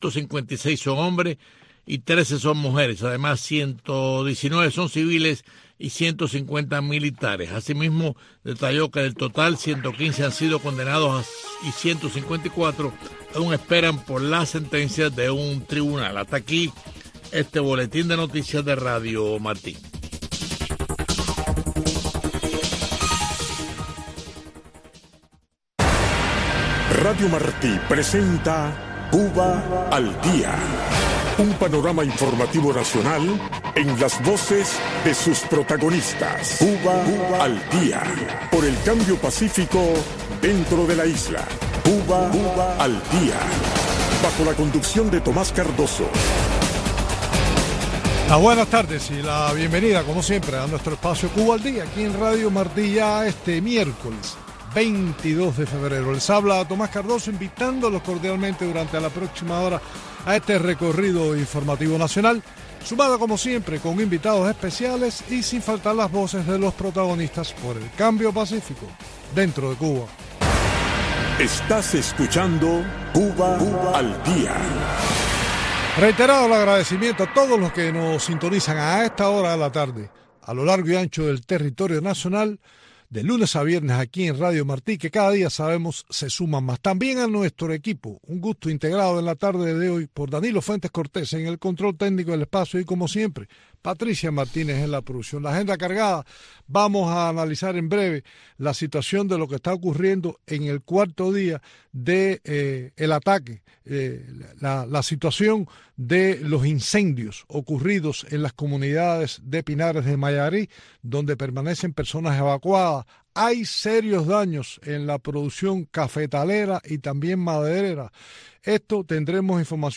conecta cada día con sus invitados en la isla en este espacio informativo en vivo